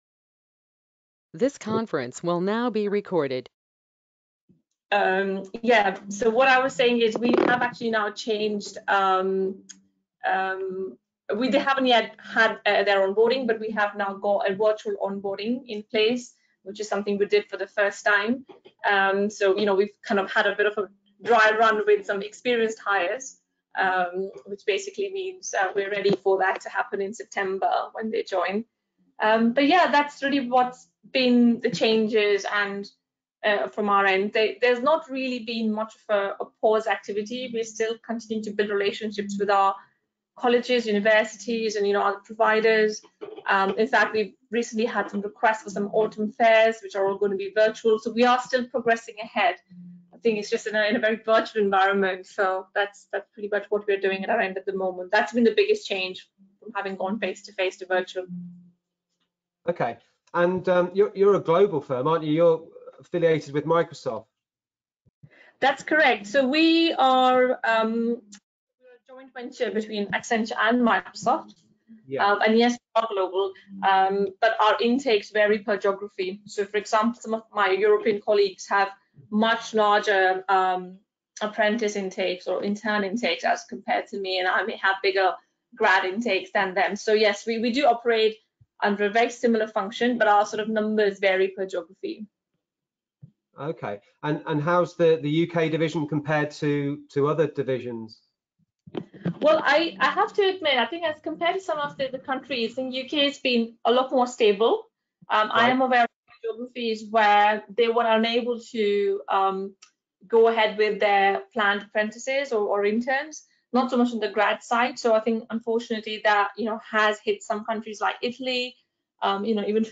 Hear from four graduate recruitment experts as we discuss what changes they are making to their recruitment plans during covid.